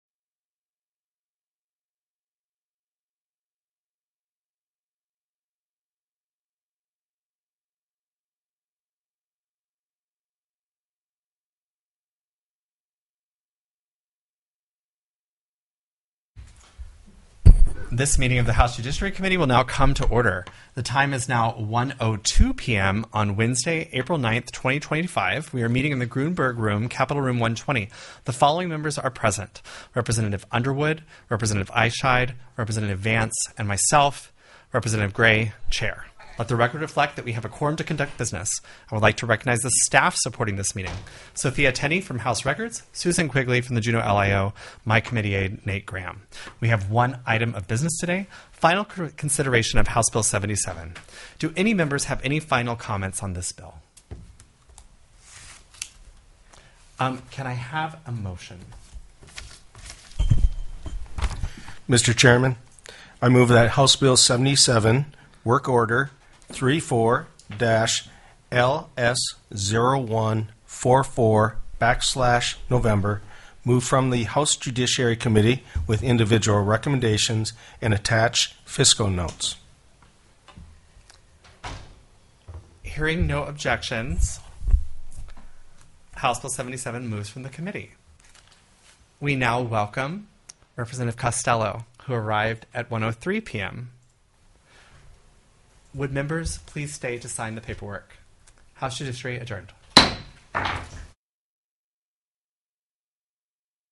The audio recordings are captured by our records offices as the official record of the meeting and will have more accurate timestamps.
+ Bills Previously Heard/Scheduled TELECONFERENCED